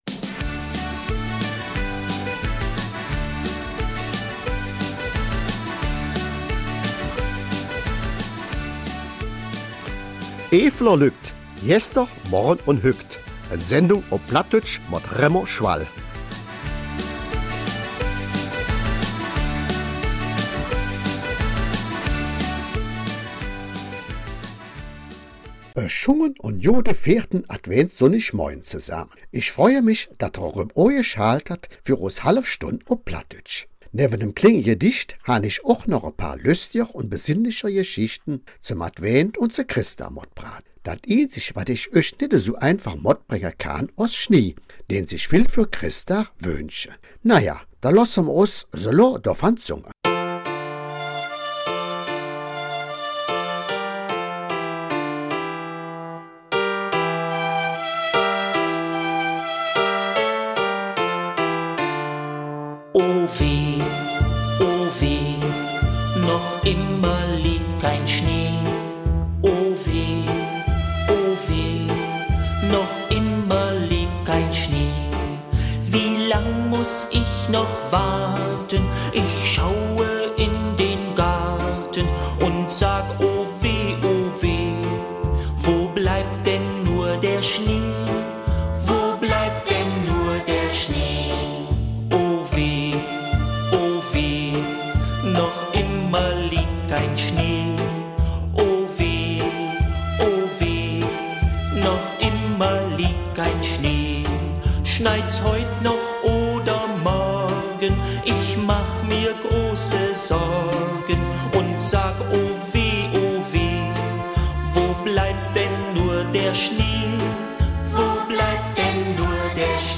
Alle Eifeler Mundartmoderatoren übermitteln außerdem persönlich ihre Weihnachts- und Neujahrsgrüße an die treuen Hörer des BRF.